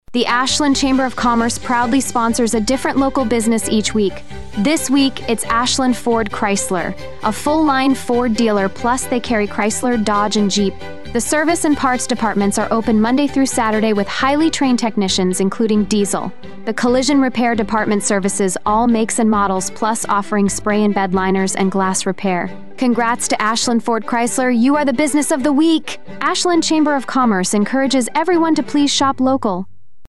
Each week the Ashland Area Chamber of Commerce highlights a business on Heartland Communications radio station WATW 1400AM and Bay Country 101.3FM. The Chamber draws a name at random from our membership and the radio station writes a 30-second ad exclusively for that business.